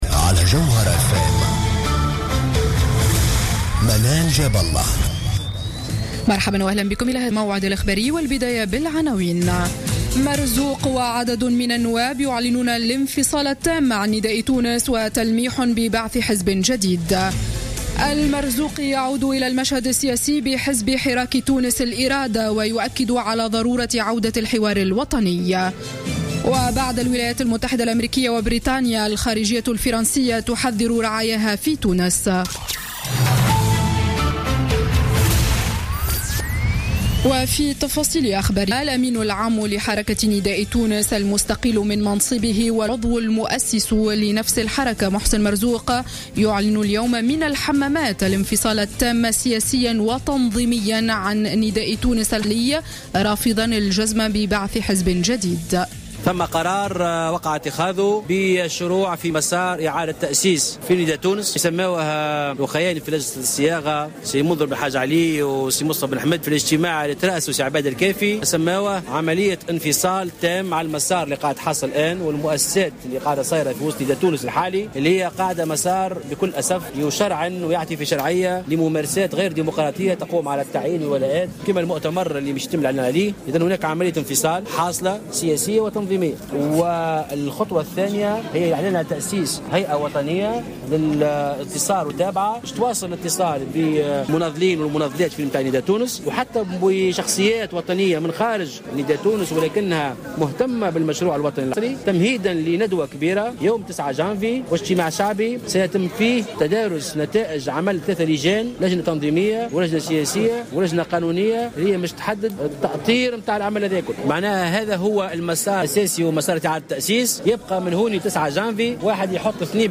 نشرة أخبار السابعة مساء ليوم الأحد 20 ديسمبر 2015